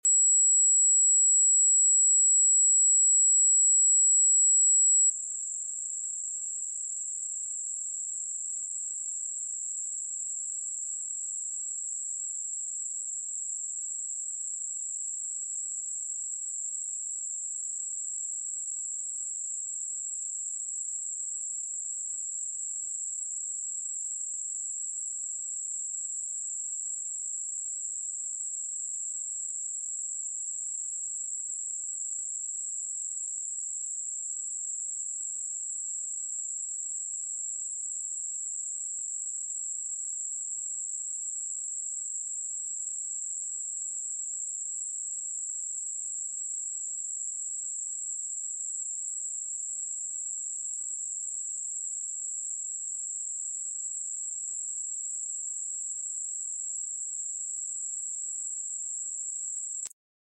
Experience the Powerful Vibrations of 7777 Hz